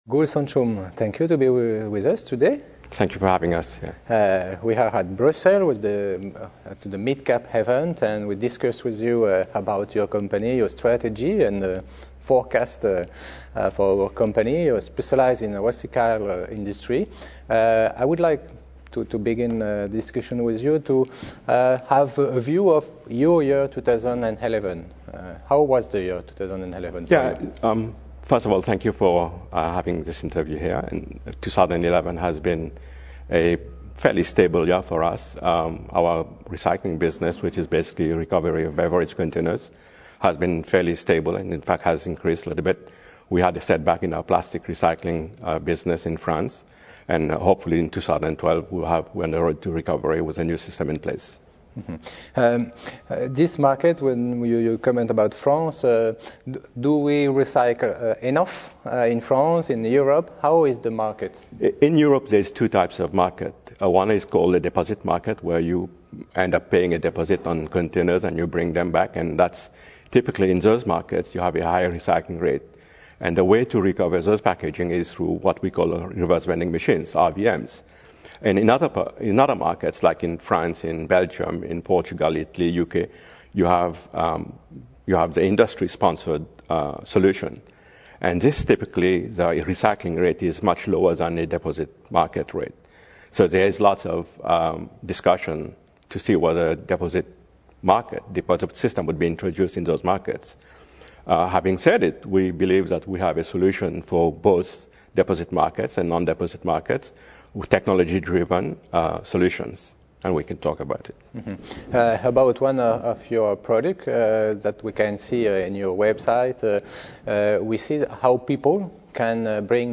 At Bruxelles Midcap Event, organized by CF&B and promoted by Nyse Euronext, we did interviews with lot of CEO.